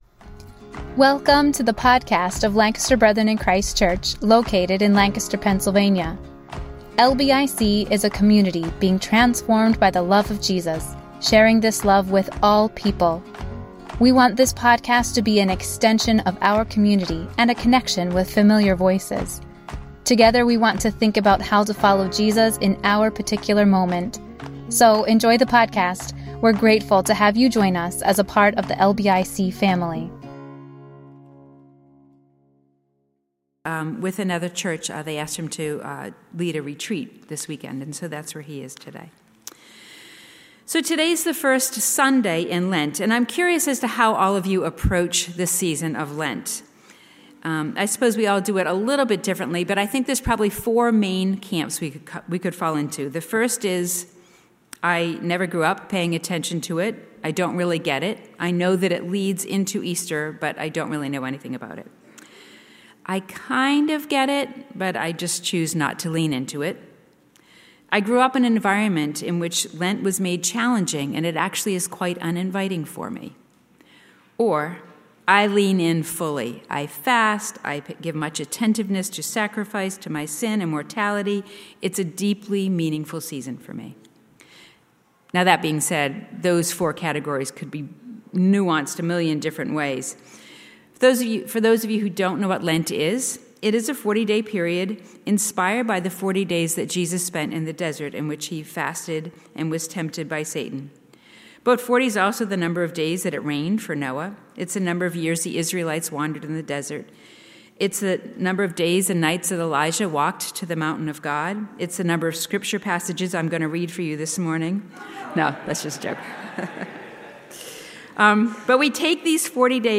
A message from the series "Lent."